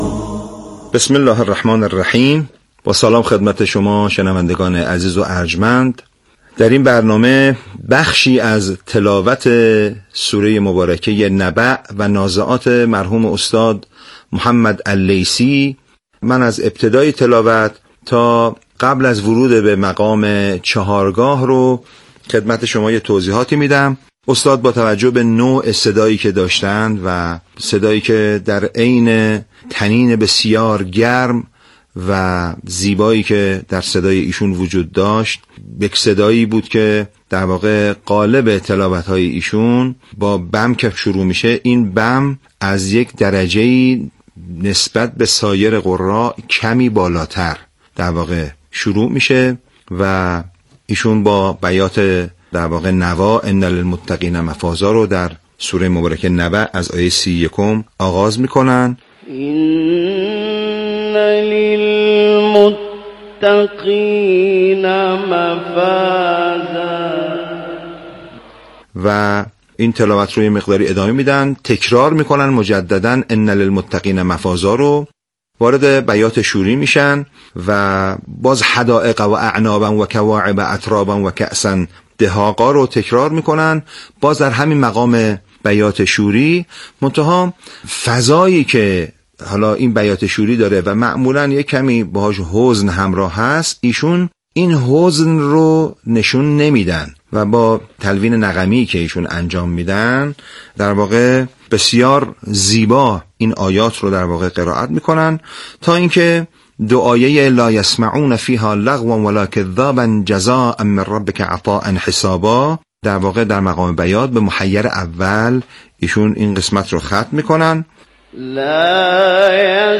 البته محمد اللیثی بم خیلی فاخری ندارد و بم او خیلی جذاب نیست، ولی در عین حال برای آماده شدن برای بخش‌هایی بعدی ترجیح داده است تا این قسمت‌ها را بم‌خوانی کند.